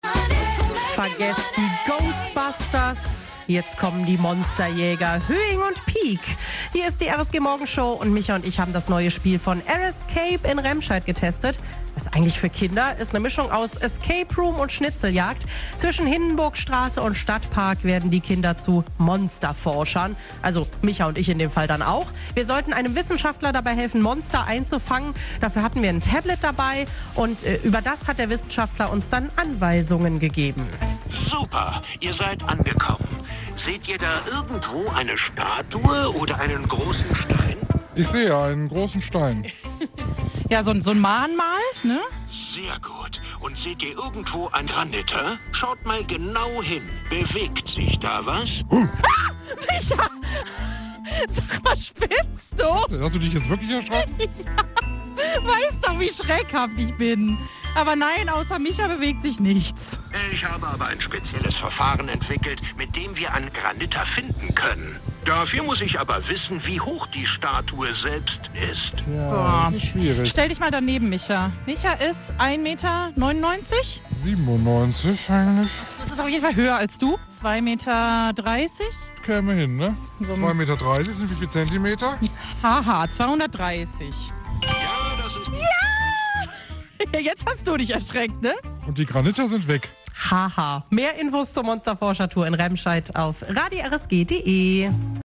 Eindrücke von der Monsterforscher-Tour